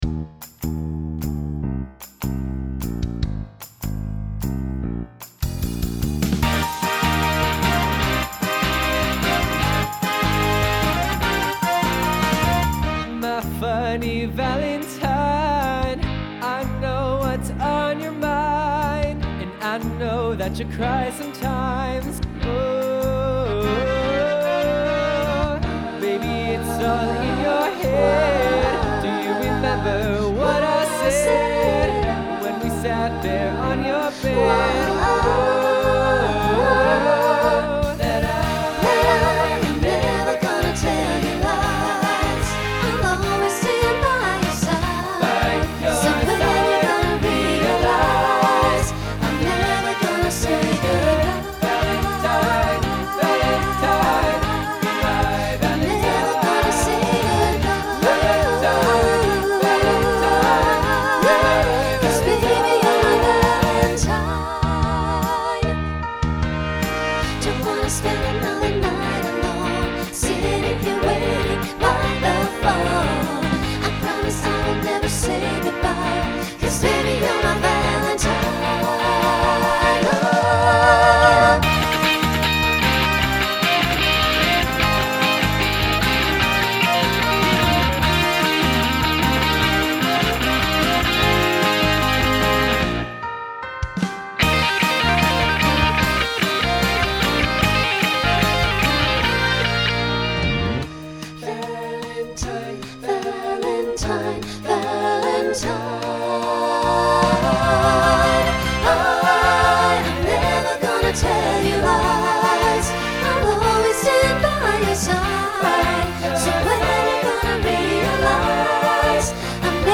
Rock Instrumental combo
Mid-tempo , Novelty Voicing SATB